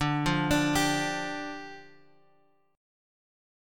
Dmbb5 chord {x 5 3 x 3 3} chord